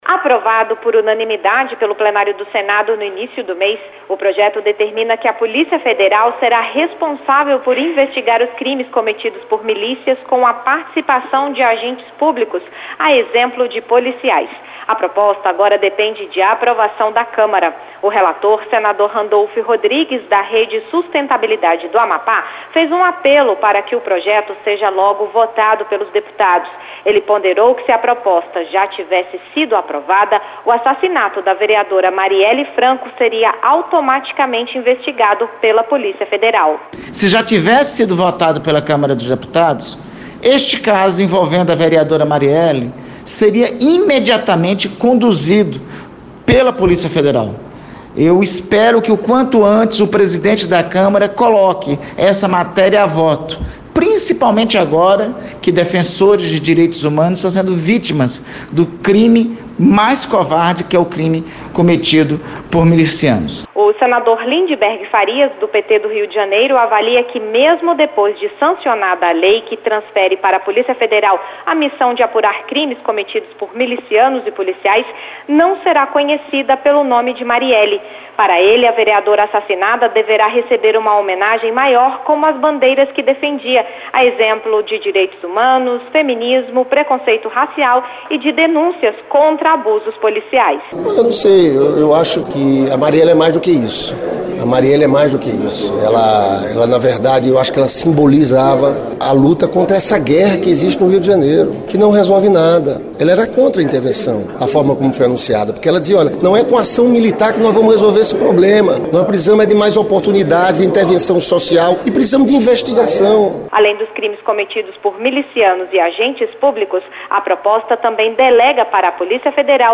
O projeto já foi aprovado no Senado e determina que a Polícia Federal será responsável por investigar os crimes cometidos por milícias. O senador Randolfe Rodrigues (Rede-AP) disse que se o projeto já tivesse sido aprovado pelos deputados, o assassinato da vereadora Marielle Franco seria automaticamente investigado pela Polícia Federal.